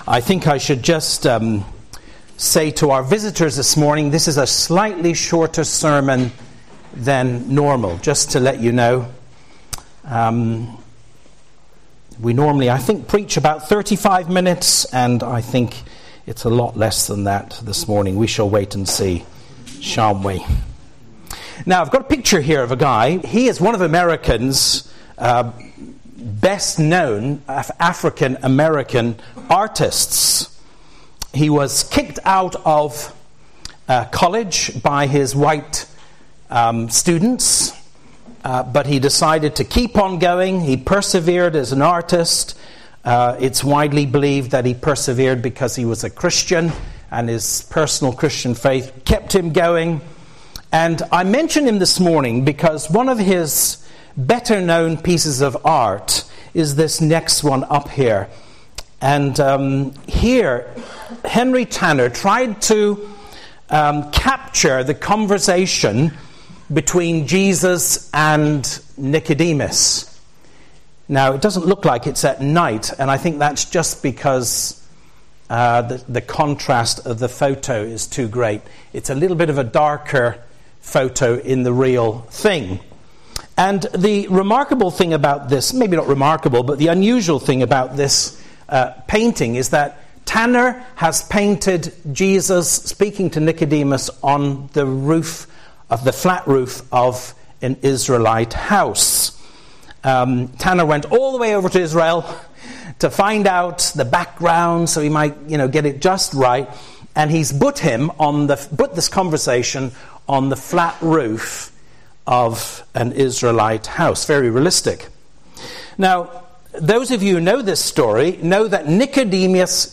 Encounters with Jesus - A Religious Man (John 3:1-22) — Manor Park Church, Worcester
Sermon Series